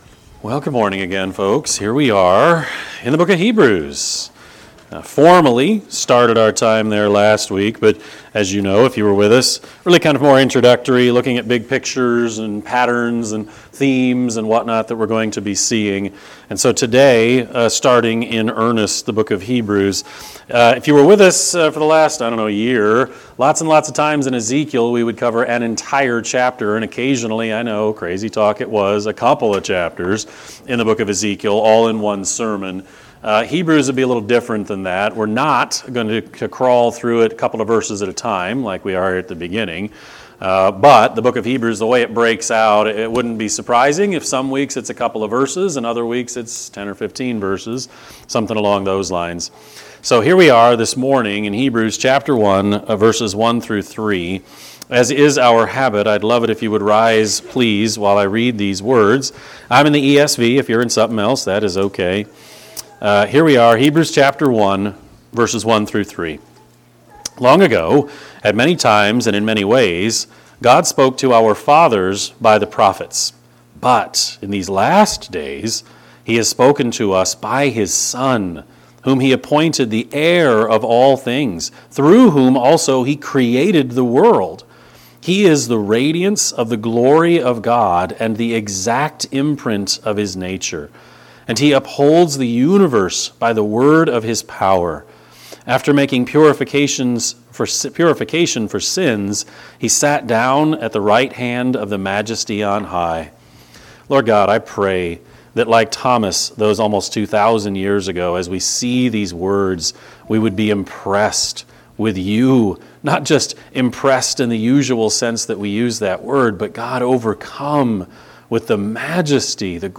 Sermon-5-25-25-Edit.mp3